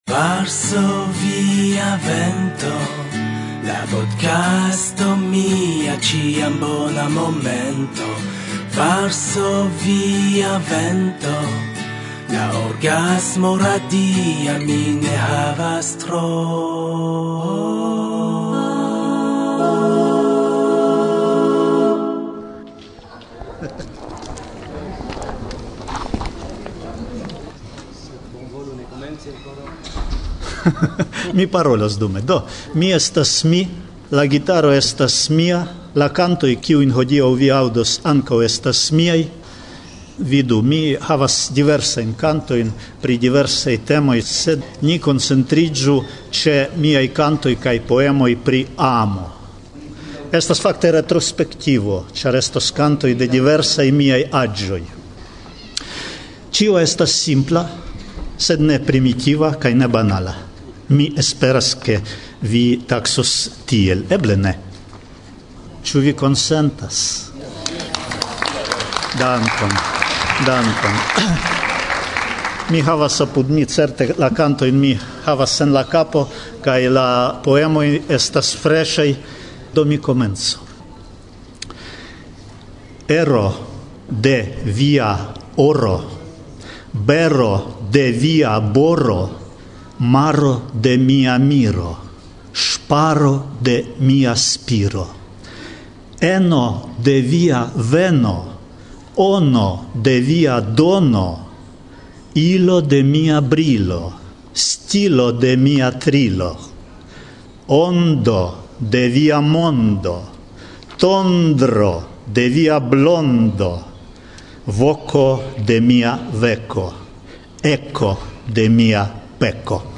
Kaj tiam, malgraŭ la kongresa bruo kaj etosa vivo preskaŭ ĝis matenoj, sukcesis trankvile sidi kaj registri la unuan intervjuon.